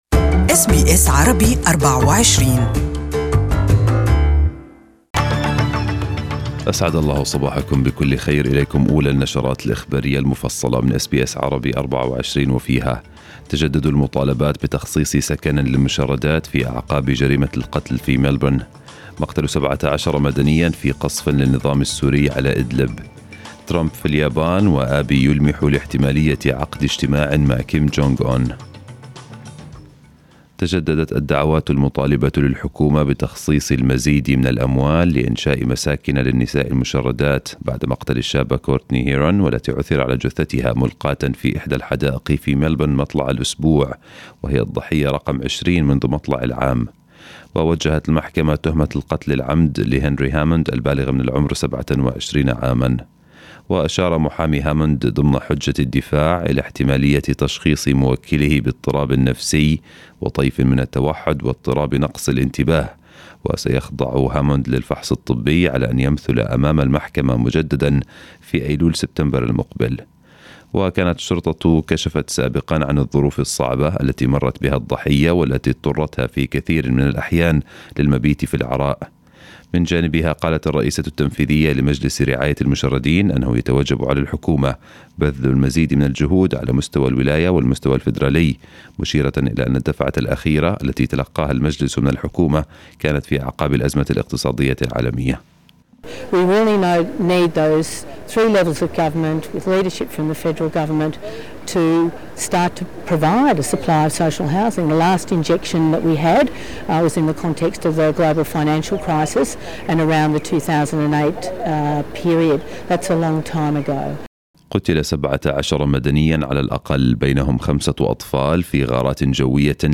Morning news bulletin in Arabic 28/5/2019